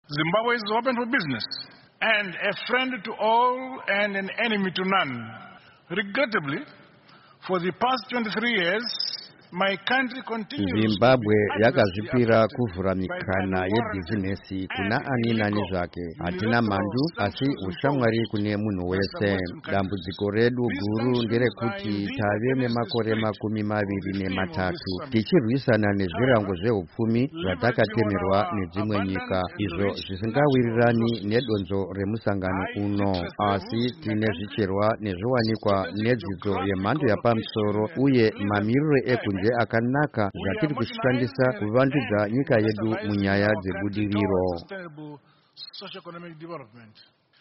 Mutungamiri wenyika VaEmmerson Mnangagwa nhasi vataura pamusangano weKorea-Africa Summit muguta guru renyika iyi reSeoul.